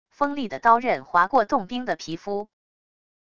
锋利的刀刃划过冻冰的皮肤wav音频